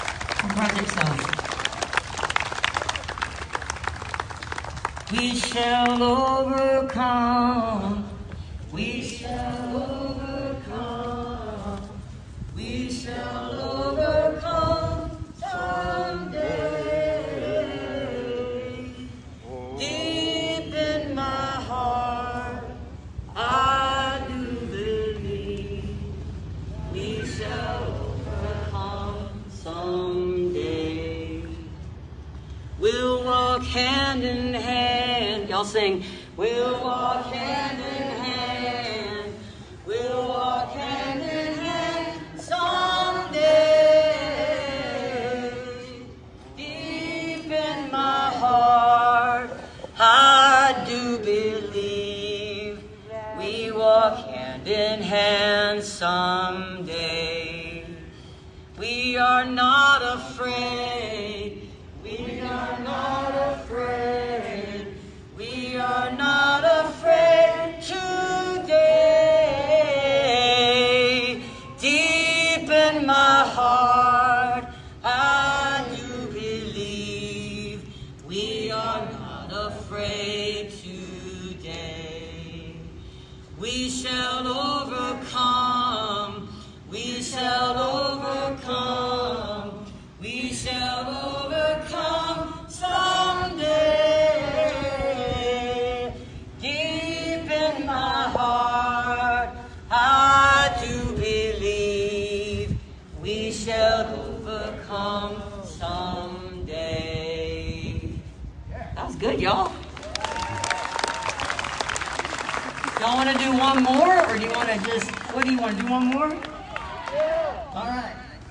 lifeblood: bootlegs: 2020-06-14: peace and unity rally at hancock park - dahlonega, georgia (amy ray)
(captured from a facebook livestream)